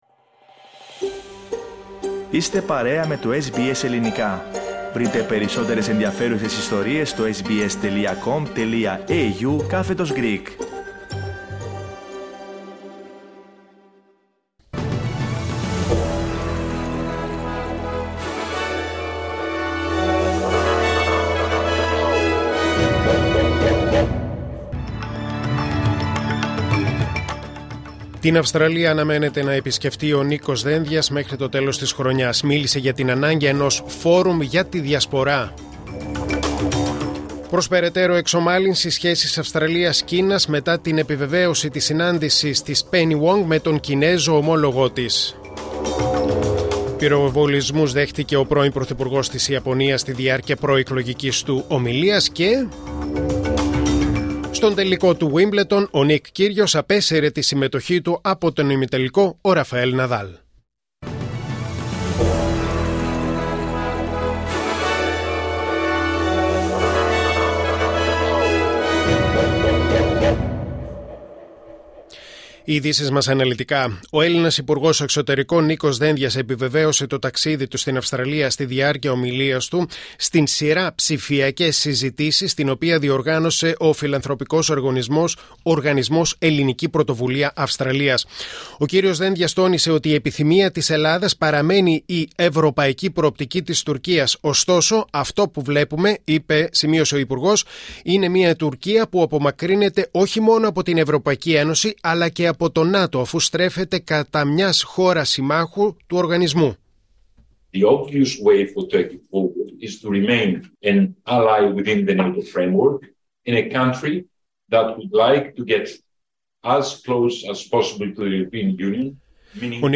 News in Greek: Friday 8.7.2022